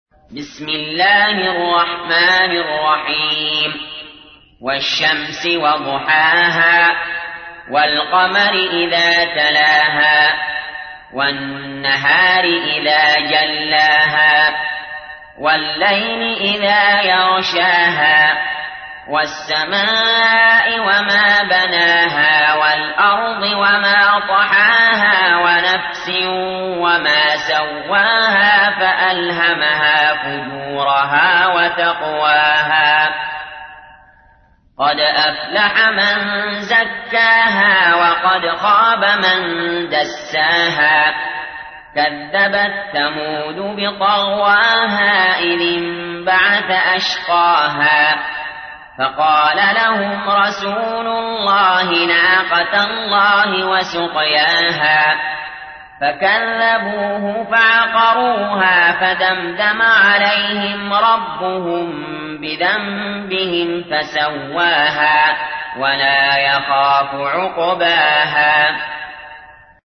تحميل : 91. سورة الشمس / القارئ علي جابر / القرآن الكريم / موقع يا حسين